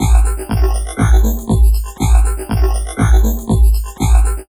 Index of /90_sSampleCDs/USB Soundscan vol.07 - Drum Loops Crazy Processed [AKAI] 1CD/Partition B/07-120FLUID